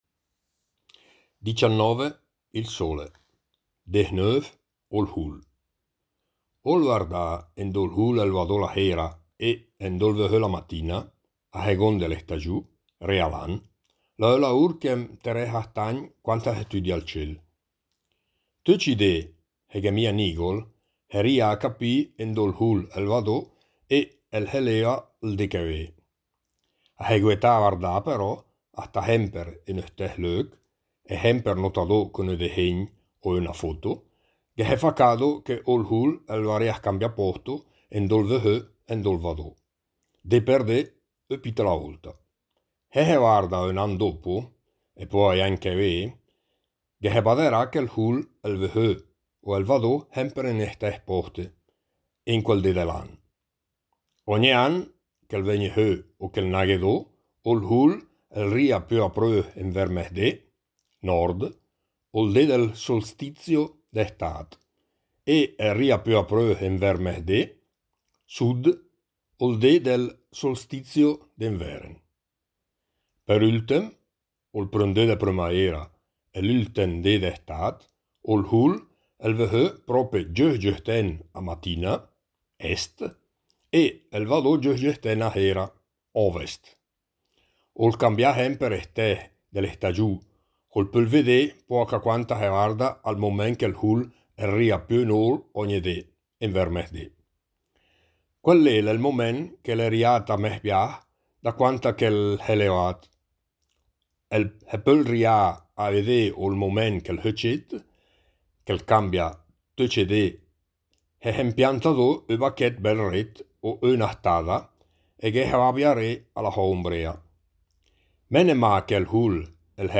Il progetto “Idiomi celesti” promuove l’osservazione ad occhio nudo del cielo stellato con testi scritti e letti ad alta voce in tutte le lingue, dialetti compresi, come ad esempio la lingua ladina e il dialetto lumezzanese.